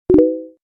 tts_notify.mp3